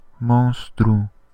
Ääntäminen
Vaihtoehtoiset kirjoitusmuodot (vanhentunut) monstre Synonyymit monstrous gigantic animal dragon fiend Ääntäminen US : IPA : [ˈmɑːn.stɜː] RP : IPA : /ˈmɒnstə(ɹ)/ Tuntematon aksentti: IPA : /ˈmɑnstɚ/ IPA : /ˈmɑːnt.stɜː/